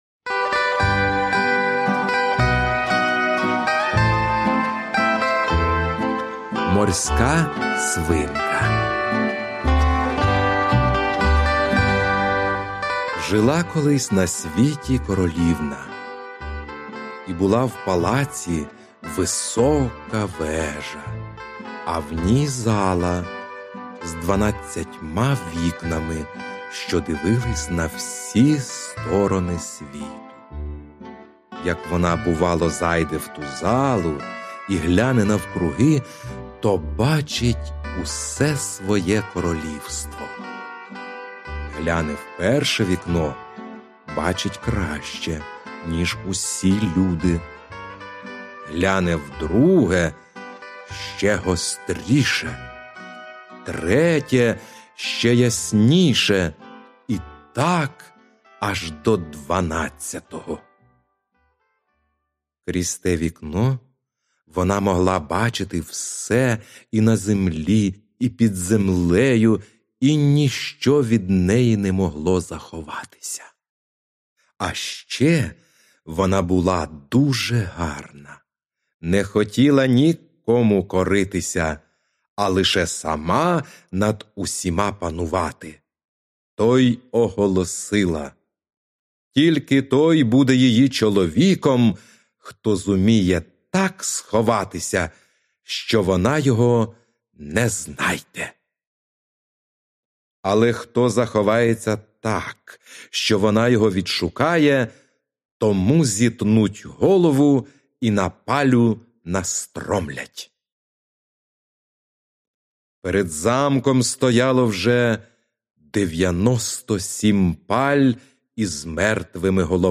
Аудіоказка Морська свинка